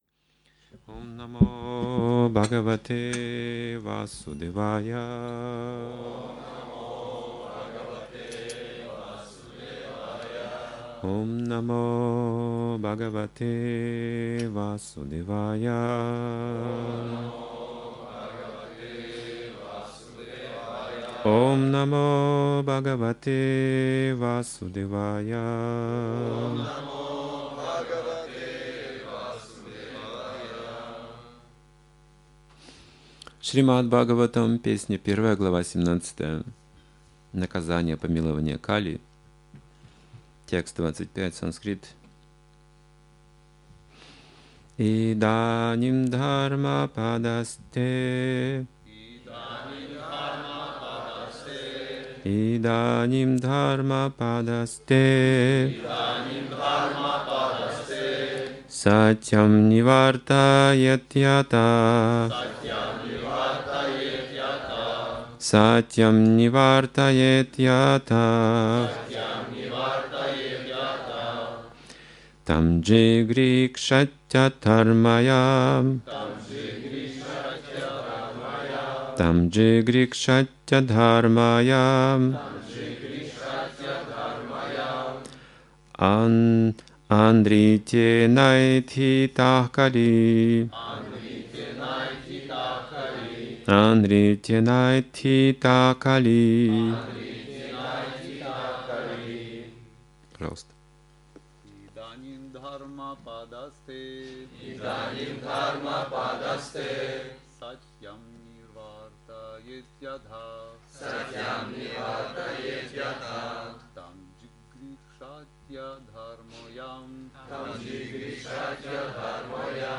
Темы, затронутые в лекции: